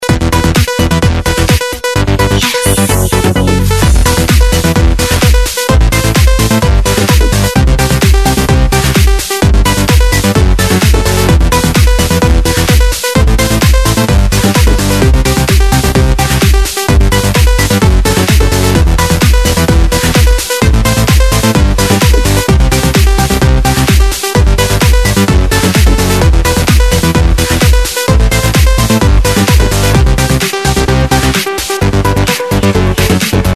Если ты любитель хауса, то тебе понравится эта нарезка!